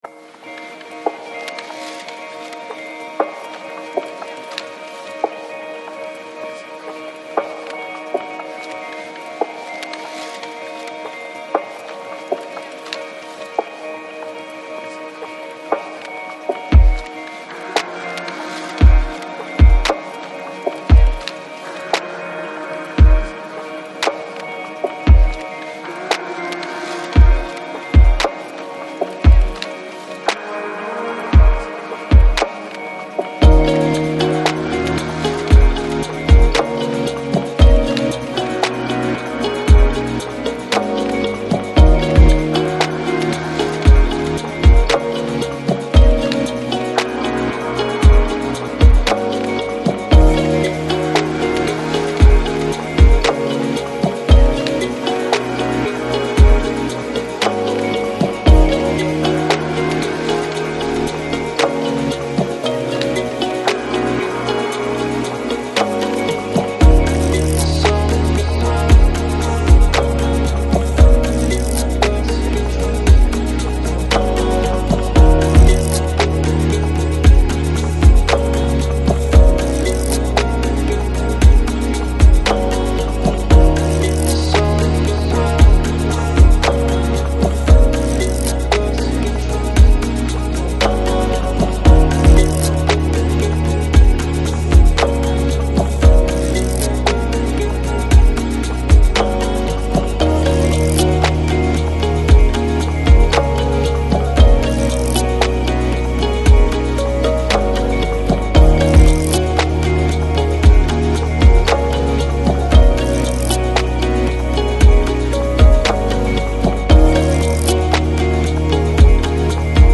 Жанр: Chill Out, Lounge, Downtempo, Balearic, Electronic